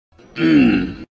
Play, download and share Sully groan original sound button!!!!
sully-groan_uiScaqZ.mp3